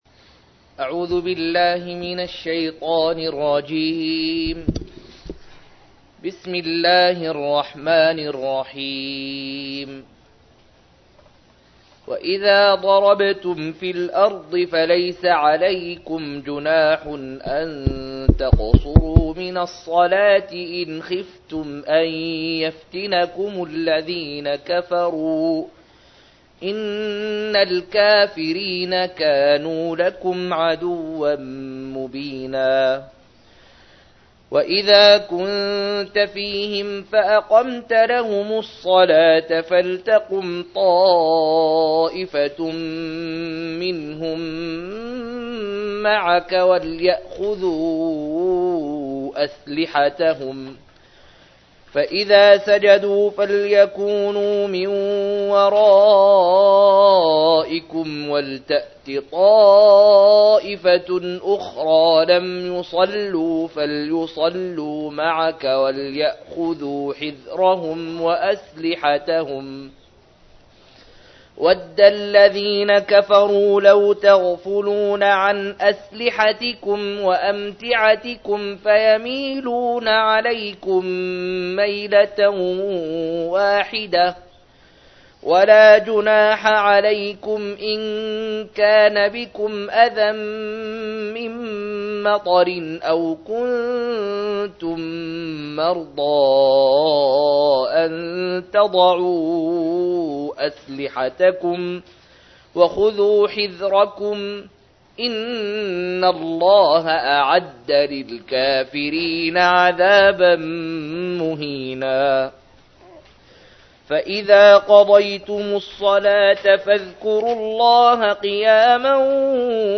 098- عمدة التفسير عن الحافظ ابن كثير رحمه الله للعلامة أحمد شاكر رحمه الله – قراءة وتعليق –